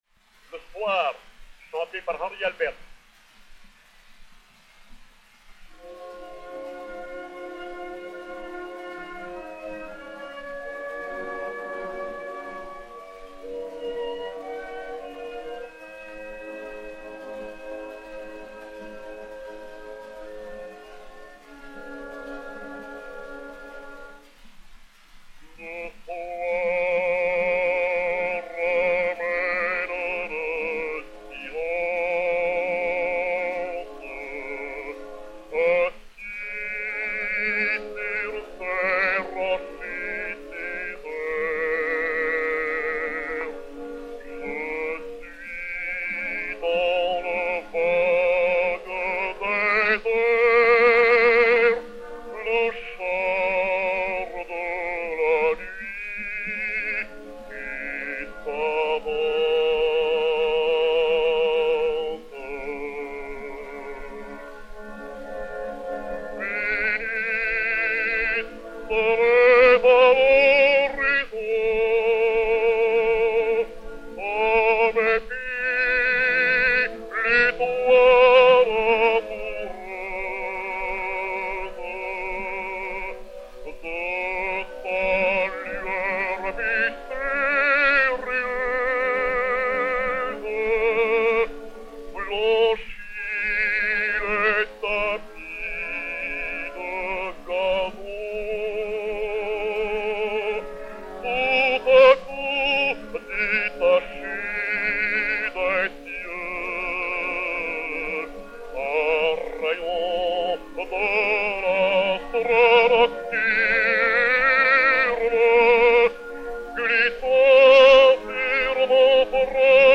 Orchestre